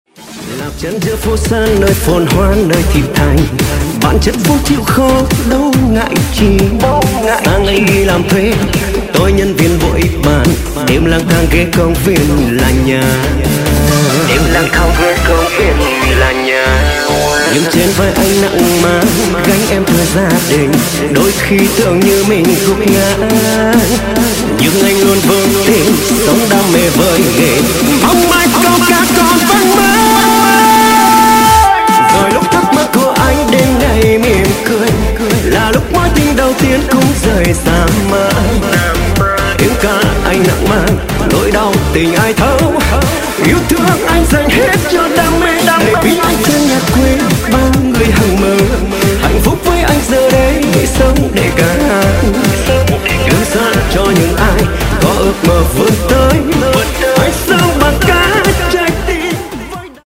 EDM/ Underground